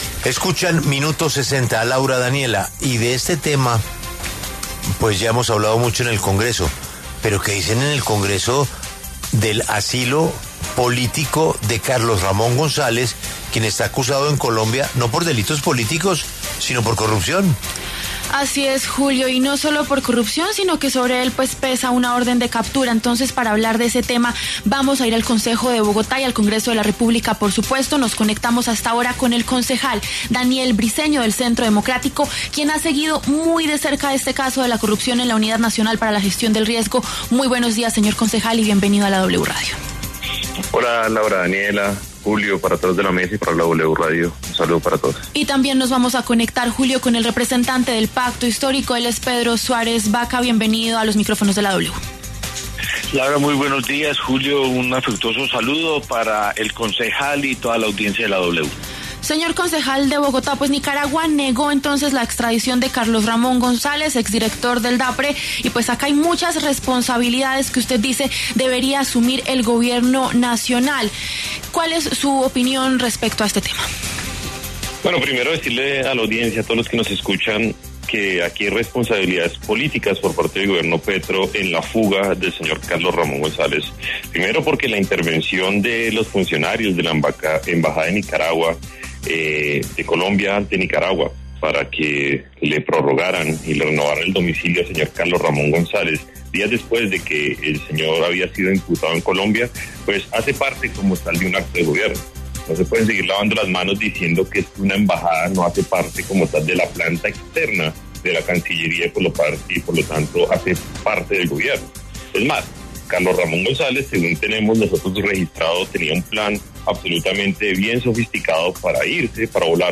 Debate: ¿Gobierno tiene responsabilidad por fuga de Carlos Ramón González a Nicaragua?
Daniel Briceño, concejal de Bogotá, y Pedro Suárez, representante del Pacto Histórico, conversaron en La W acerca de la decisión tomada por Nicaragua con respecto a la estadía en ese país de Carlos Ramón González.